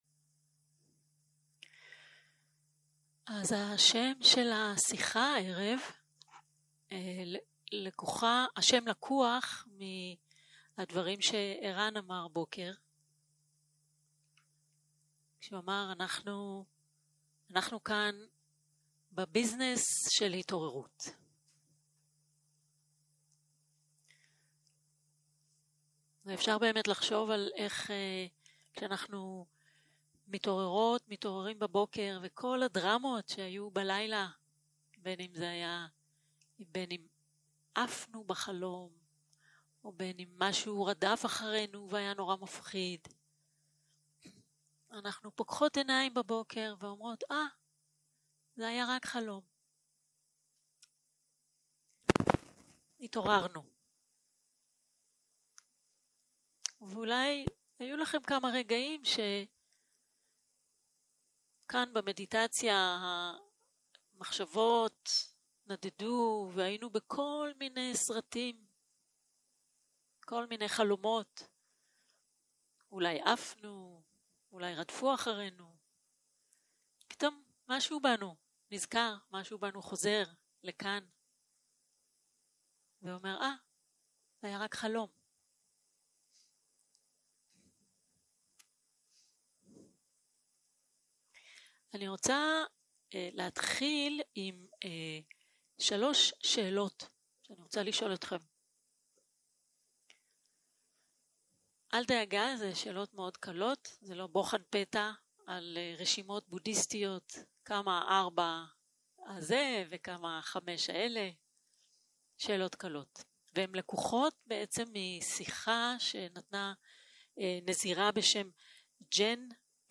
Dharma type: Dharma Talks שפת ההקלטה